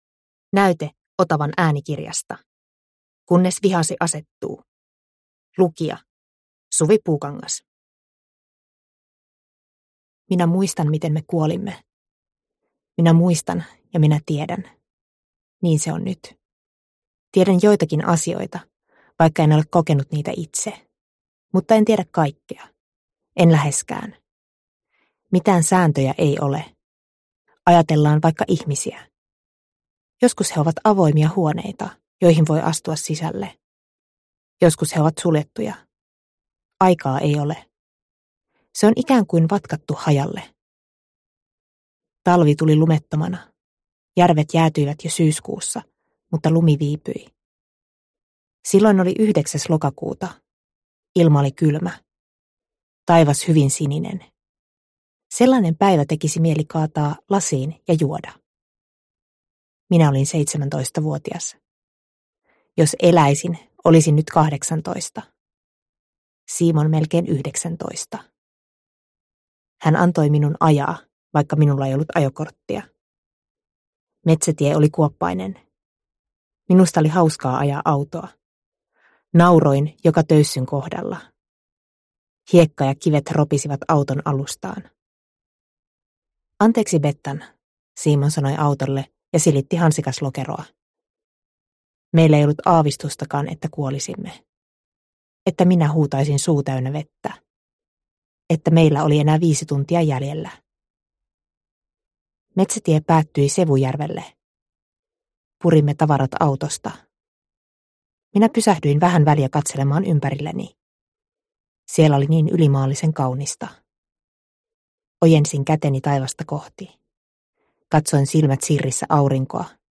Kunnes vihasi asettuu – Ljudbok – Laddas ner